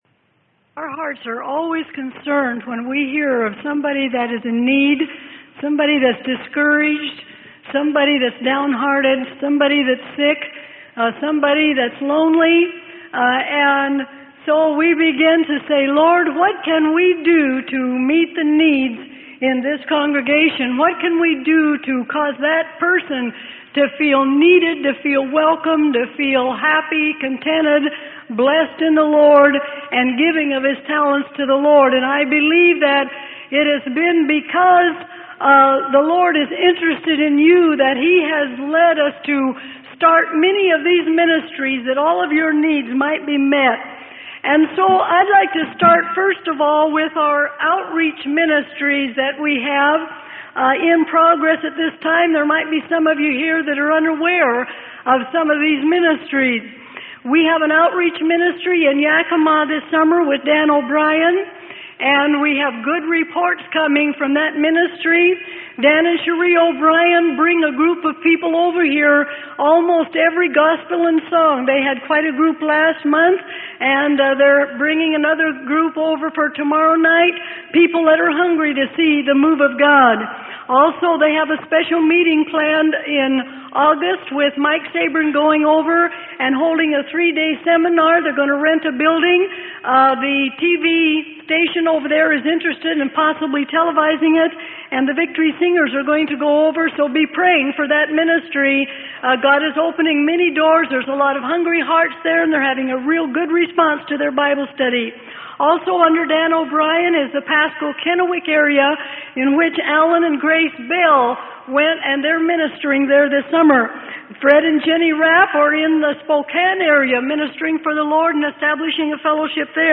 Sermon: God'S Hand Reaches Out Through Ministries At Community Chapel.